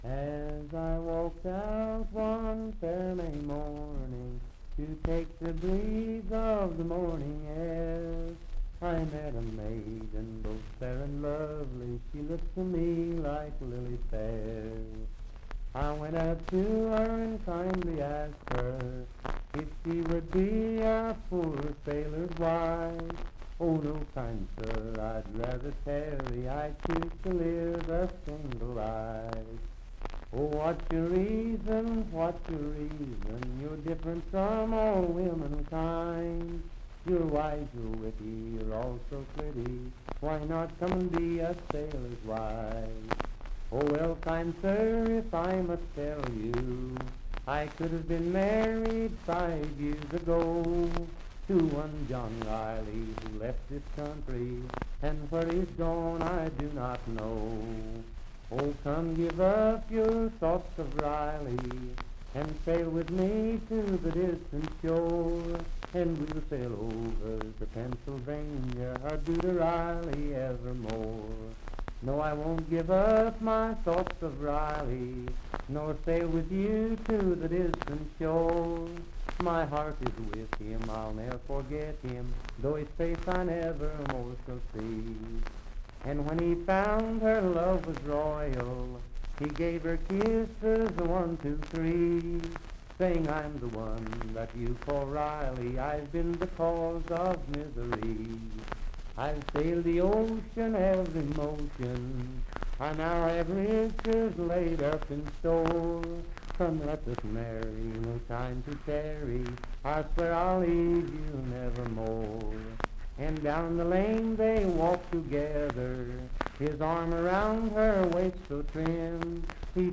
Unaccompanied vocal music
Verse-refrain 9(4).
Voice (sung)